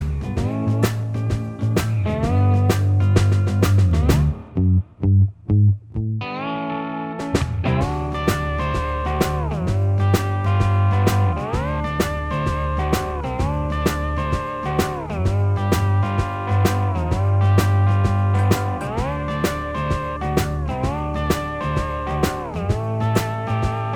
Minus Acoustic Guitar Pop (1960s) 2:29 Buy £1.50